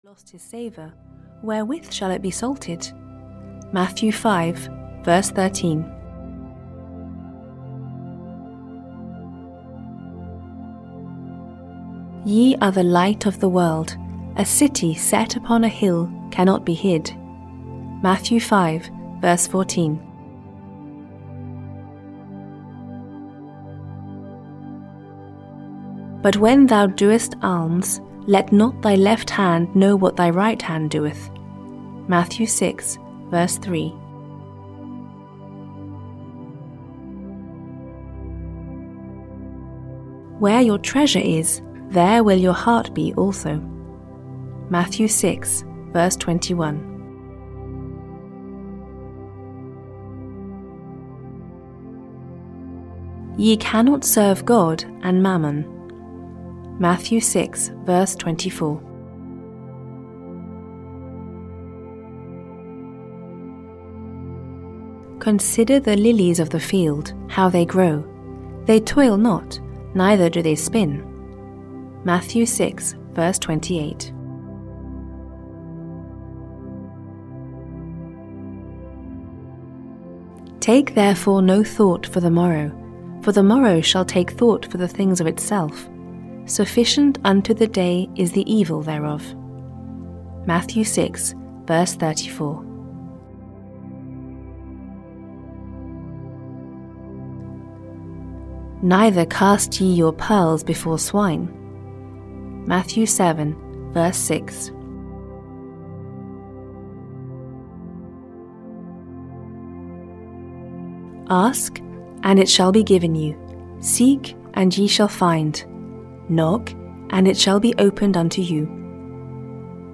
Audio kniha200 Quotes from the Holy Bible, Old & New Testament (EN)
Ukázka z knihy